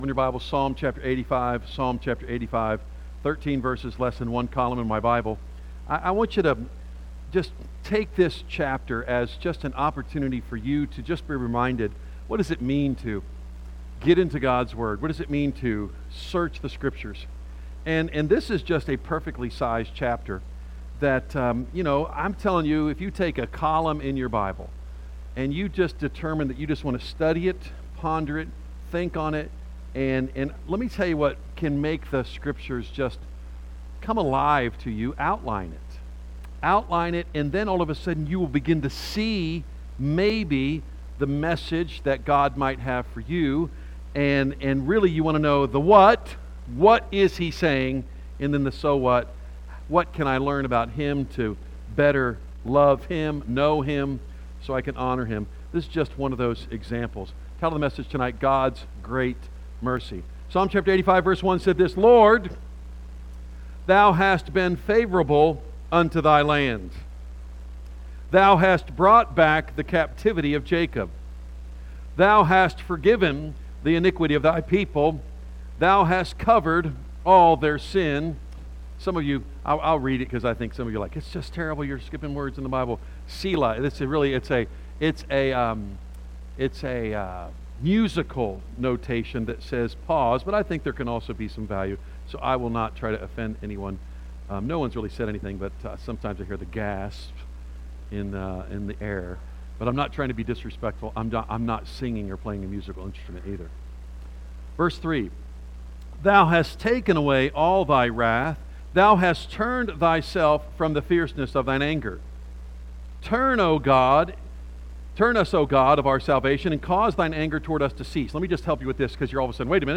A message from the series "Psalms."